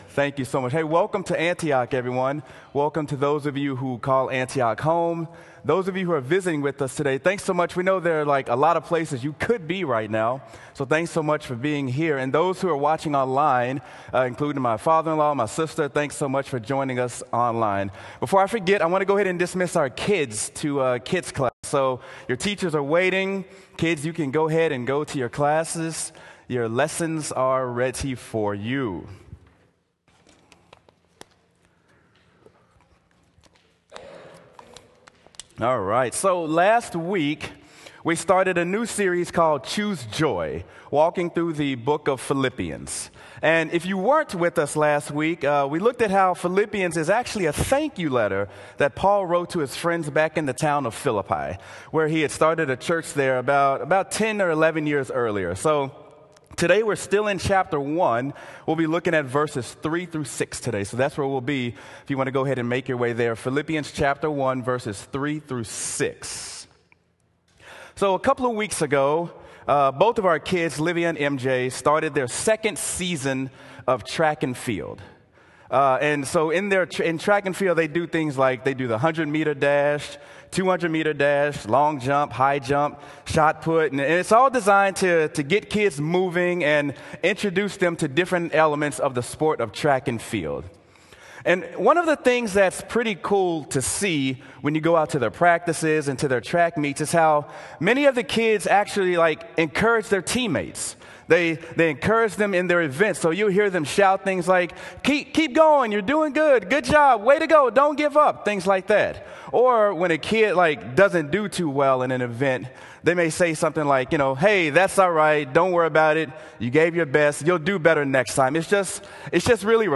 Sermon: Choose Joy: Three Ways to Encourage Other Believers